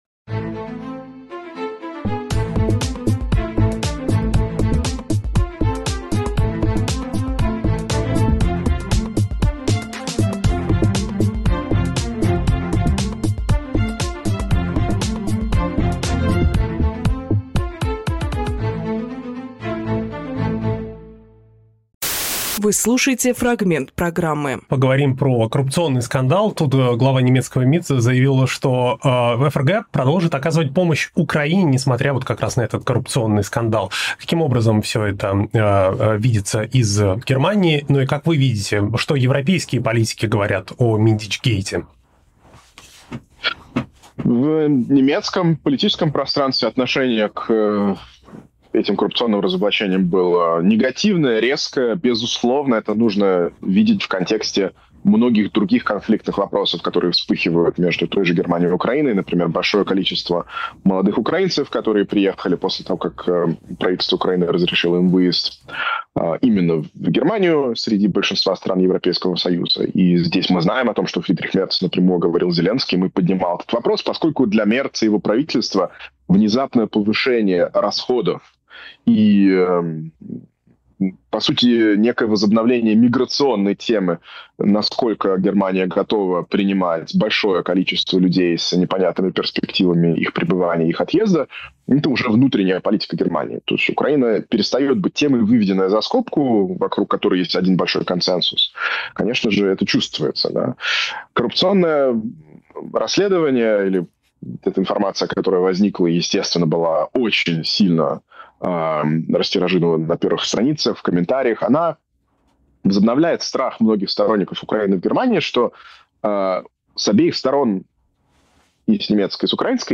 Фрагмент эфира от 21.11.25